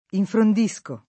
infrond&Sko], -sci